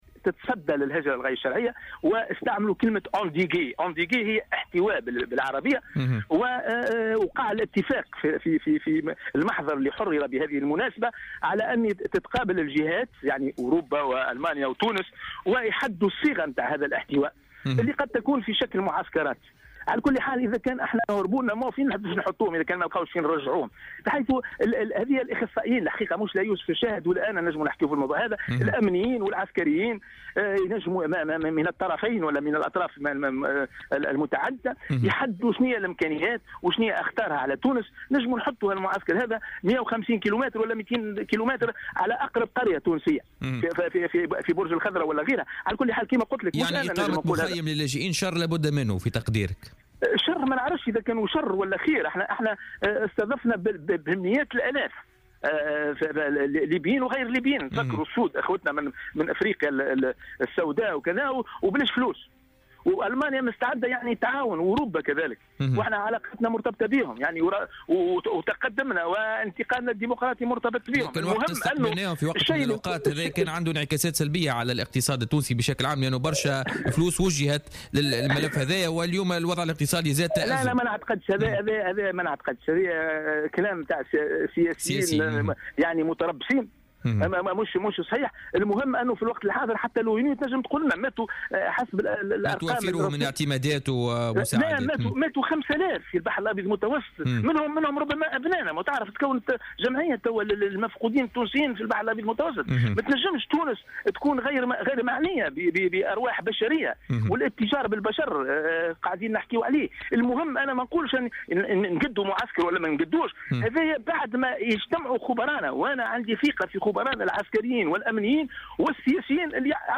المحلل السياسي
في مداخلة له في بوليتيكا